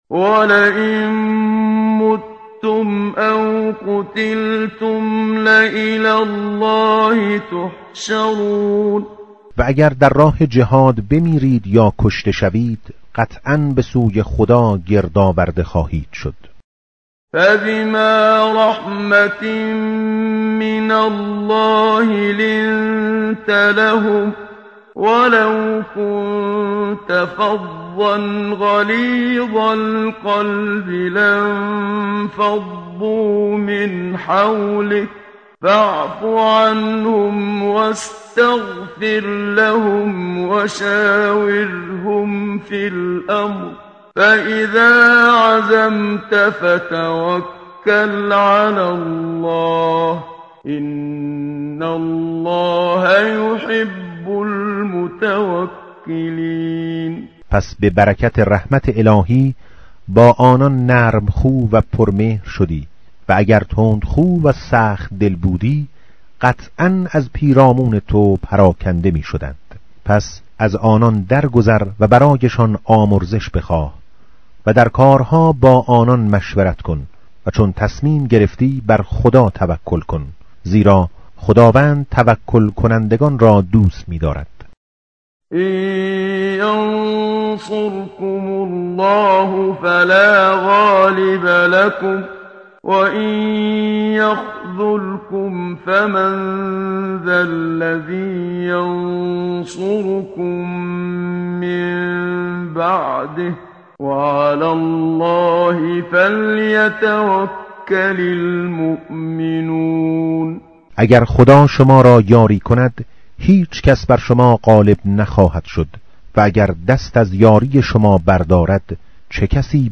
tartil_menshavi va tarjome_Page_071.mp3